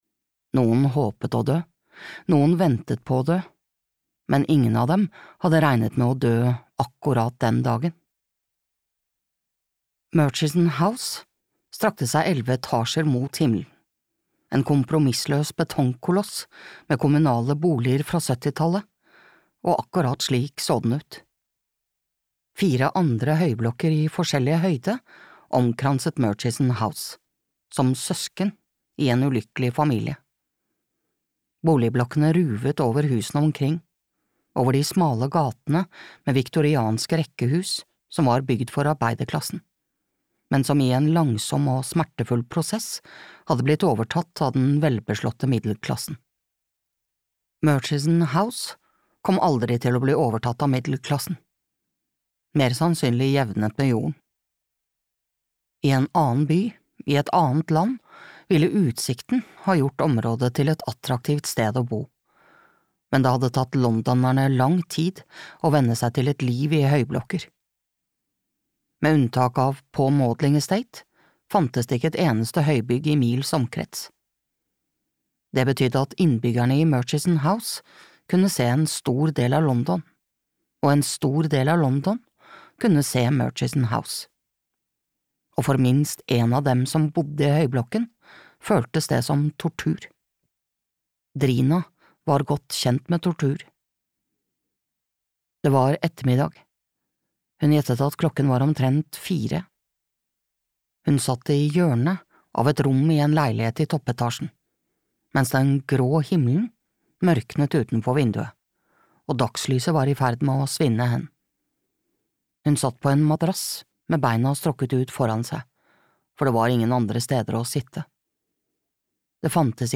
Etter brannen (lydbok) av Jane Casey